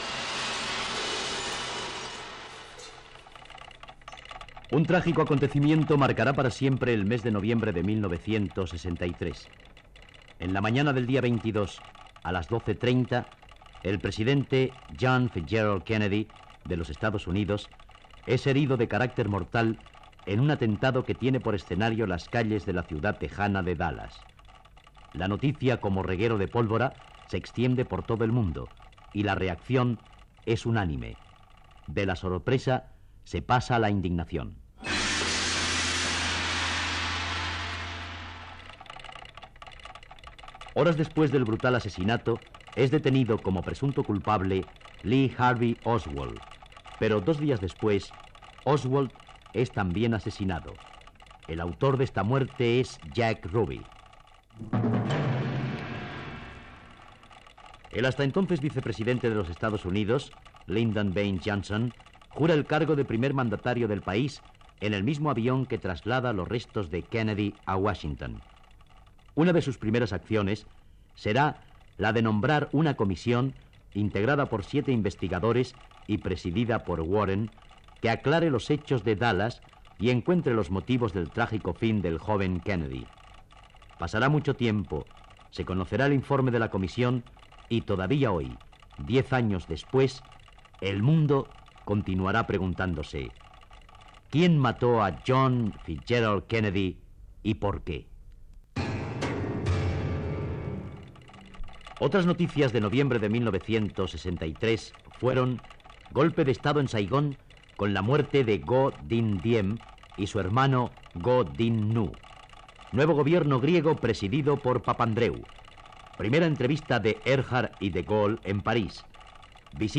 Careta del programa
Informatiu